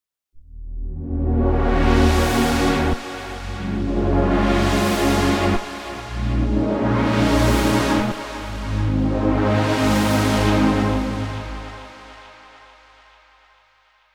Вот пример - пилообразный пэд с 21 унисоном на голос, 5 голосов полифонии всего (аккорды по 5 нот). Ничего страшного, никакой каши.
Вложения Test Pad 105 voices.mp3 Test Pad 105 voices.mp3 552,6 KB · Просмотры: 436